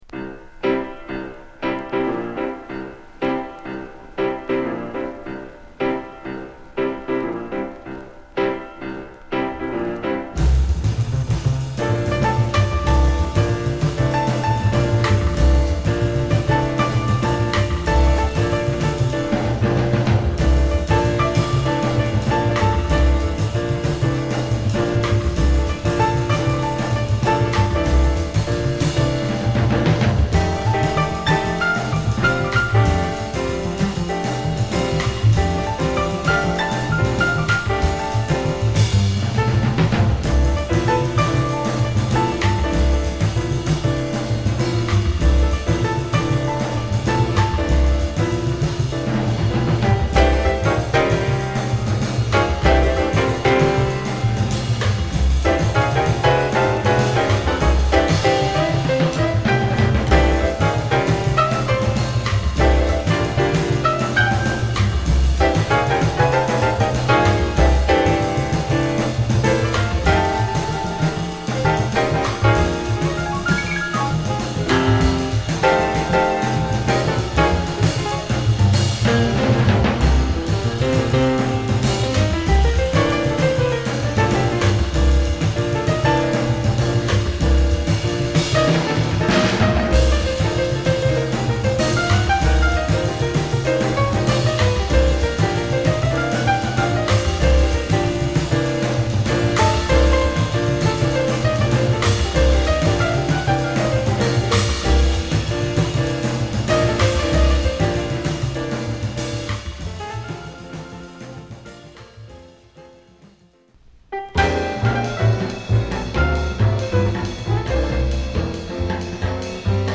ドラム、ベース、ピアノのトリオ物で